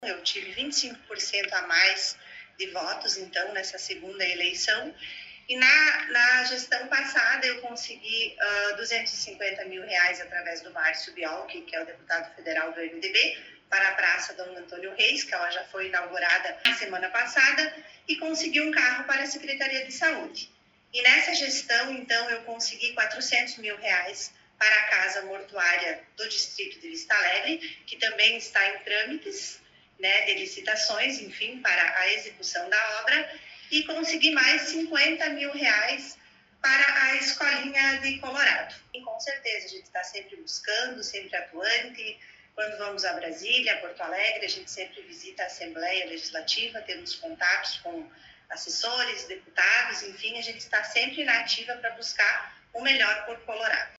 A nova Presidente do Poder Legislativo concedeu entrevista